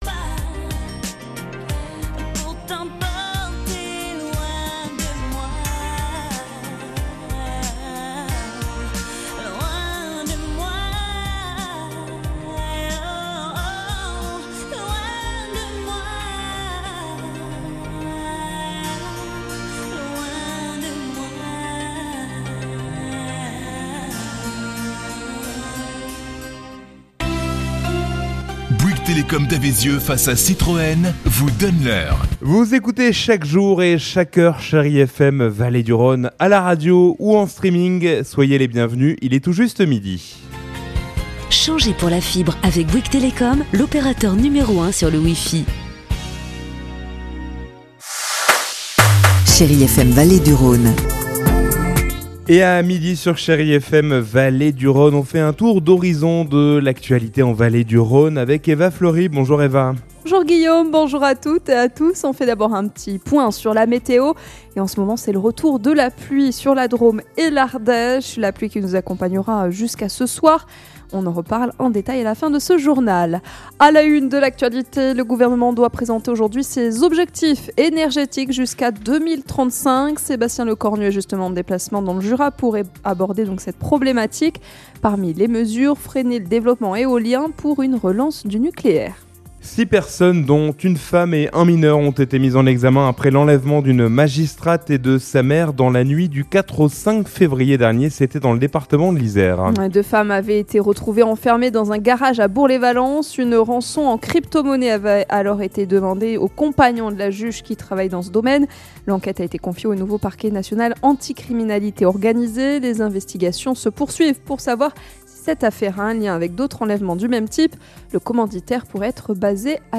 Jeudi 12 février : Le journal de 12h